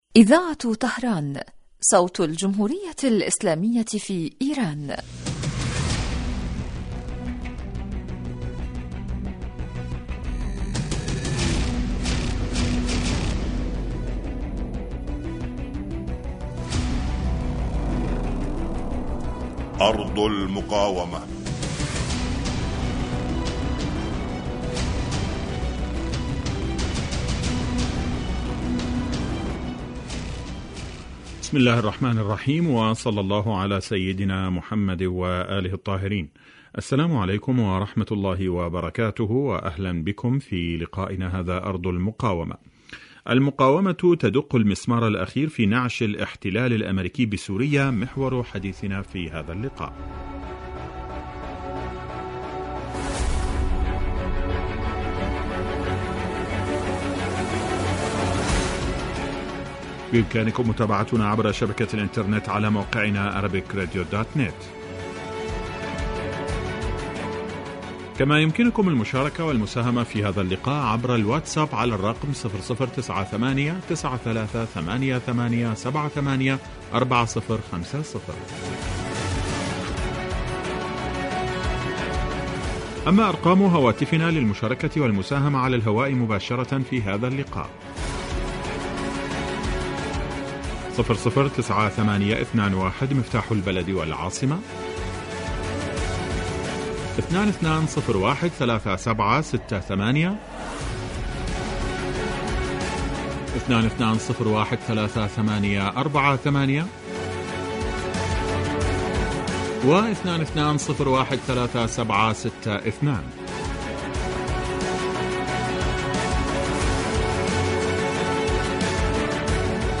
برنامج إذاعي حي لنصف ساعة يتناول بالدراسة والتحليل آخر التطورات والمستجدات على صعيد سوريا والأردن وفلسطين المحتلة ولبنان
يستهل المقدم البرنامج بمقدمة يعرض فيها أهم ملف الأسبوع ثم يوجه تساؤلاته إلى الخبراء السياسيين الملمين بشؤون وقضايا تلك الدول والذين تتم استضافتهم عبر الهاتف